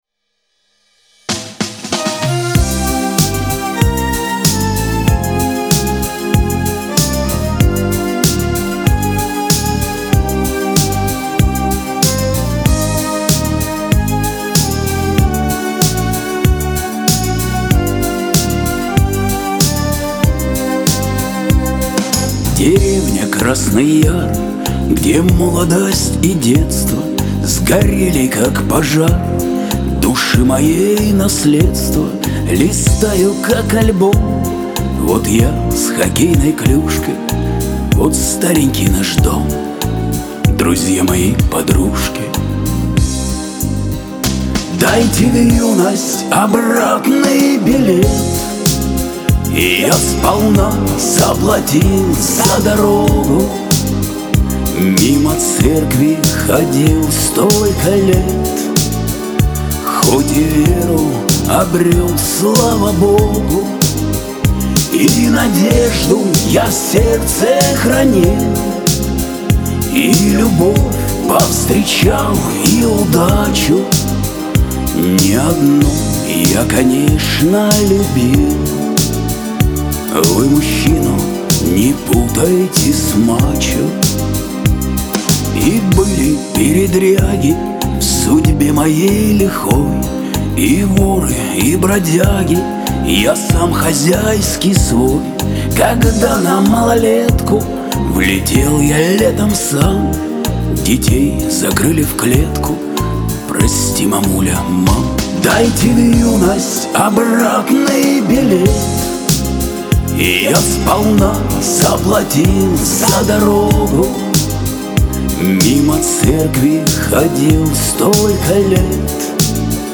Шансон
грусть
Лирика